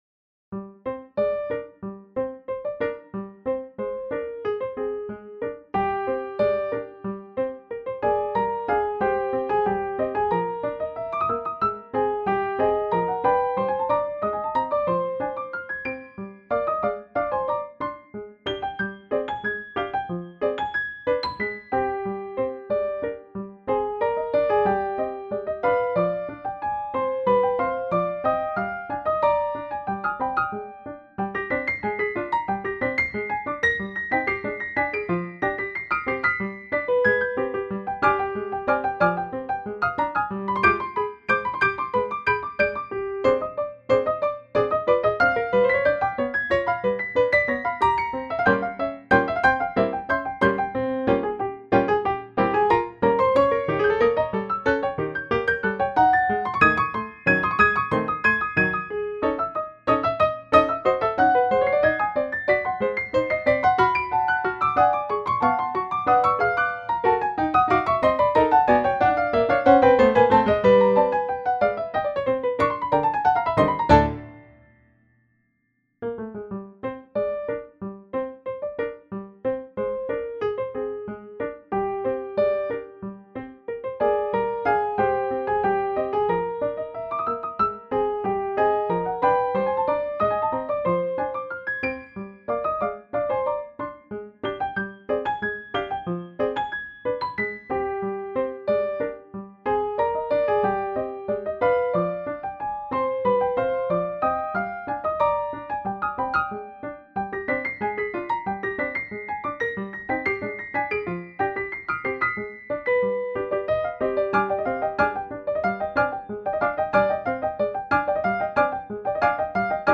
2 pianos
parts: piano 1, piano 2